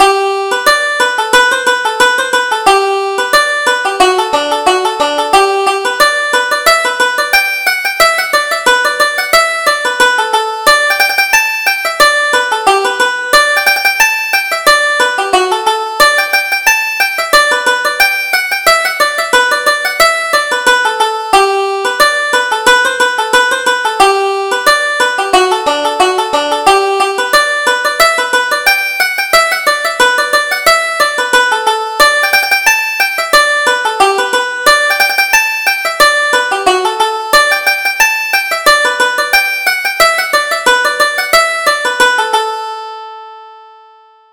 Reel: The Grey Plover